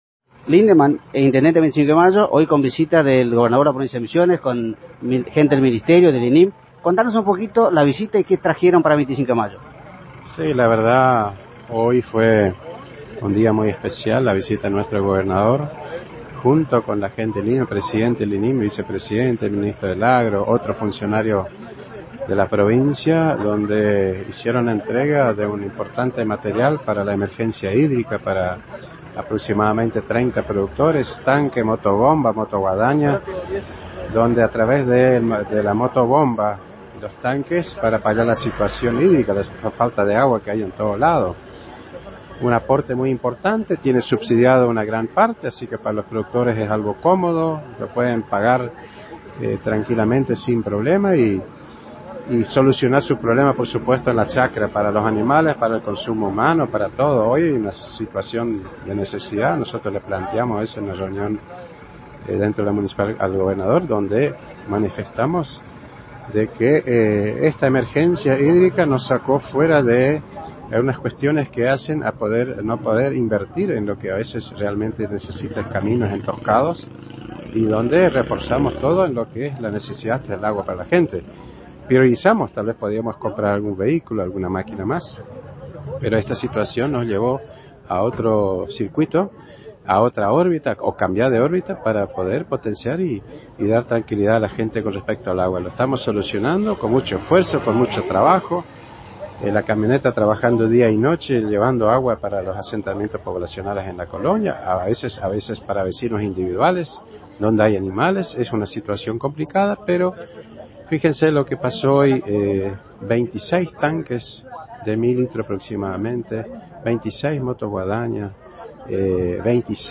En una jornada de entrega de herramientas hídricas en el predio de la municipalidad donde contó con la presencia del Gobernador Oscar Herrera Ahuad, Ministro del Agro Sebastián Oriozabala, el cuerpo técnico y el Presidente del INYM Juan José Szychowski, entre otras funcionarios.